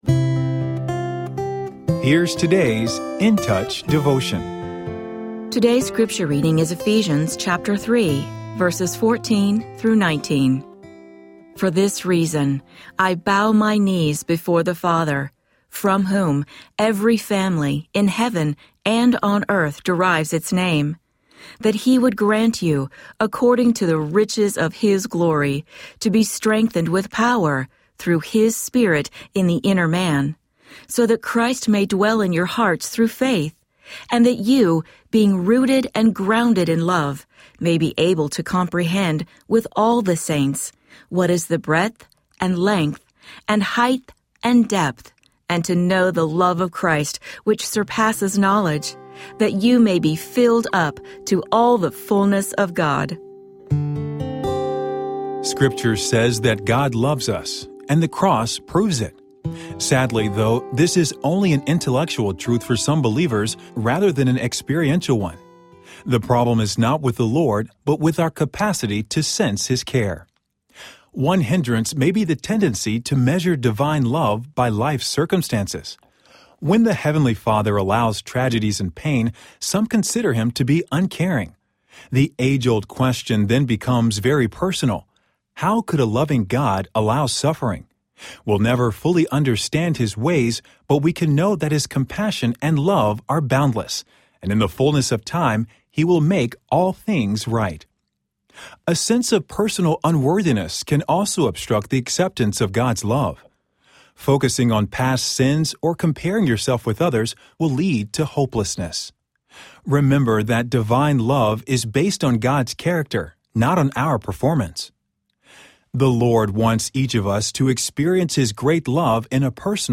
Daily audio devotional from Charles Stanley’s In Touch Ministries.